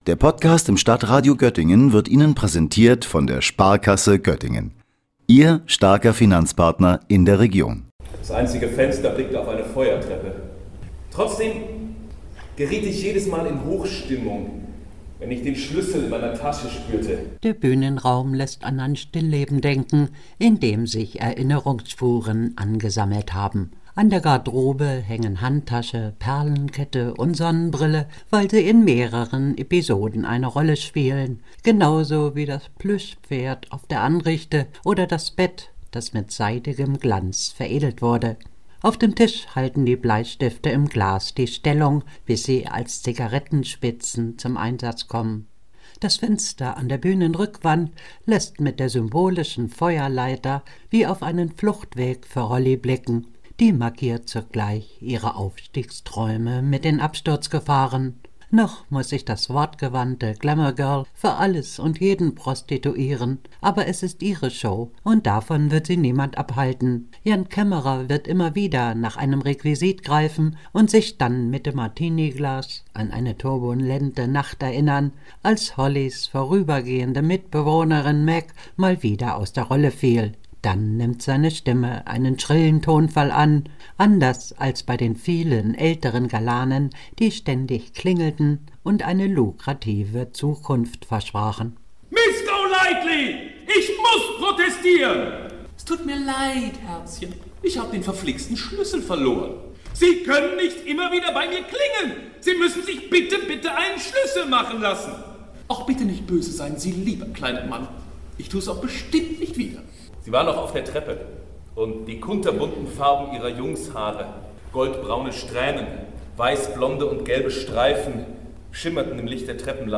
O-Ton 2, Einspieler, „Frühstück bei Tiffany“, 28 Sekunden